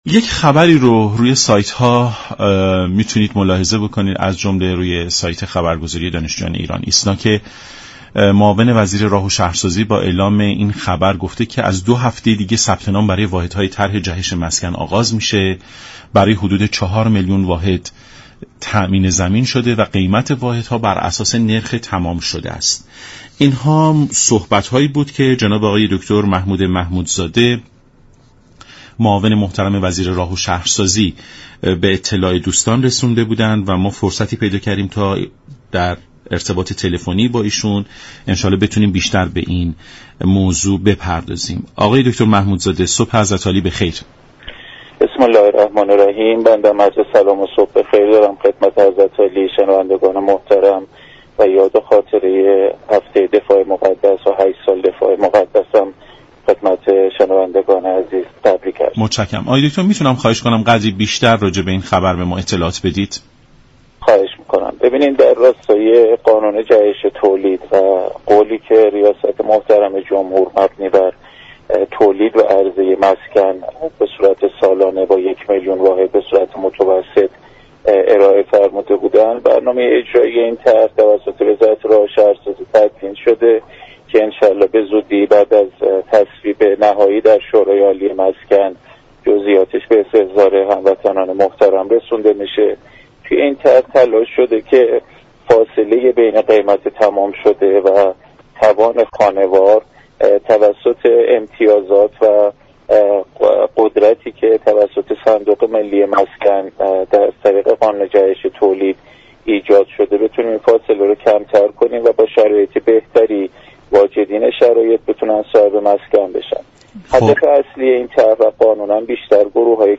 به گزارش شبكه رادیویی ایران، «محمود محمودزاده» معاون وزیر راه و شهرسازی در برنامه «سلام صبح بخیر» رادیو ایران از ثبت نام خانه اولی‌ها در طرح جهش مسكن از دو هفته آینده خبر داد و گفت: در راستای قانون جهش تولید و فرمایش رییس جمهور مبنی بر تولید و عرضه یك میلیون مسكن در سال، وزارت راه و شهرسازی برنامه ای را برای اجرای این طرح تدوین كرده است.